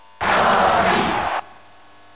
Kirby Crowd Cheer in Dairantou Smash Brothers
Kirby_Cheer_Japanese_SSB.ogg